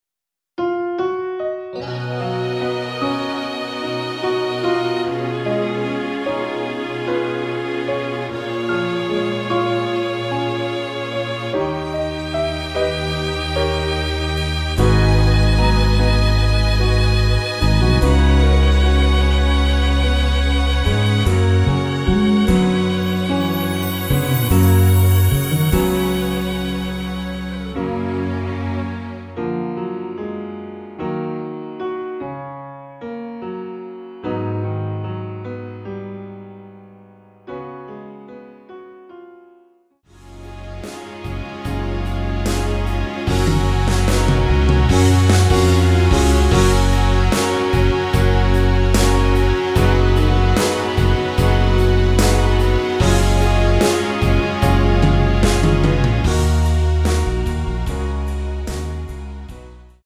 원키에서(-1)내린 MR입니다.
F#
앞부분30초, 뒷부분30초씩 편집해서 올려 드리고 있습니다.